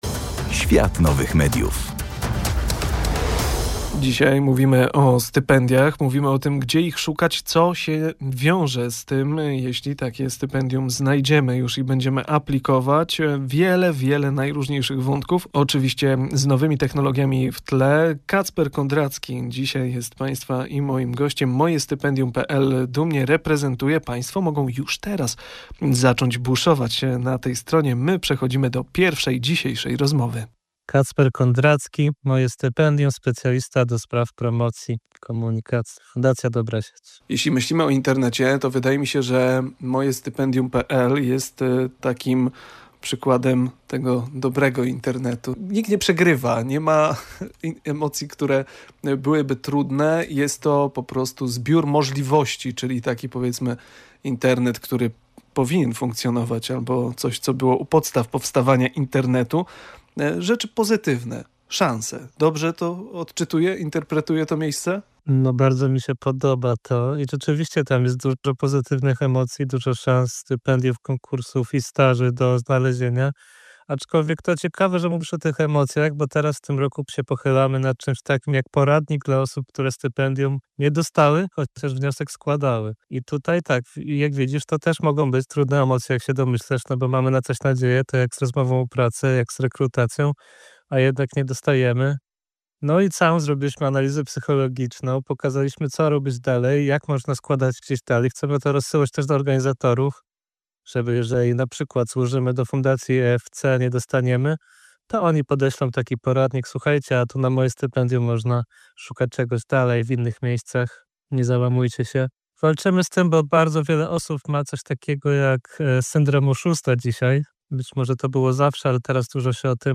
W trakcie naszej rozmowy padają ważne zdania: taka dostępność informacji to fundament, na którym buduje się nowoczesny rozwój osobisty.